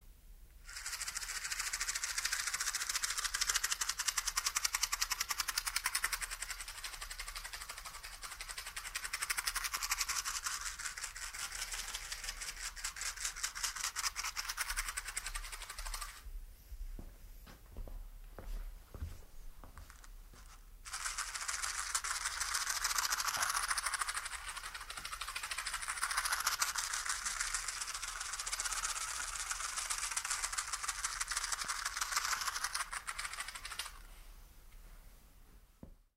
Звук Машем коробкой спичек возле уха (аудио-АСМР) (00:36)
аудио-АСМР